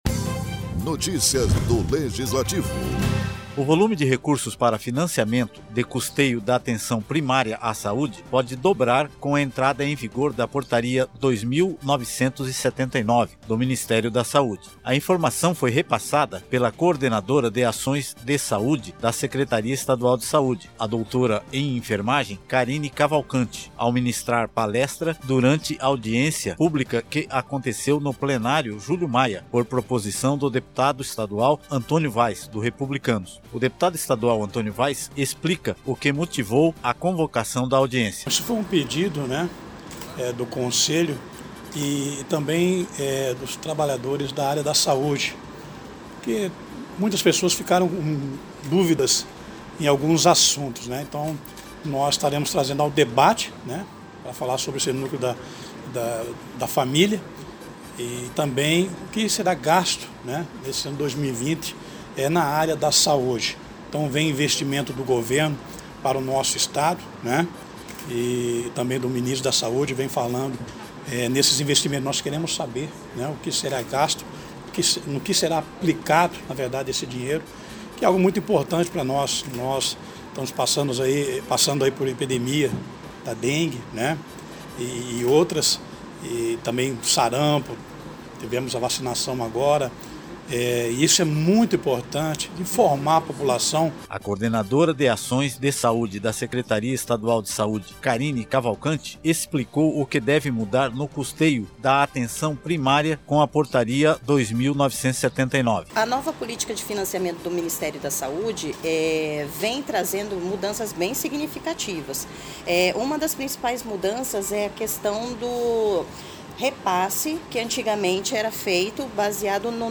Locução e Produção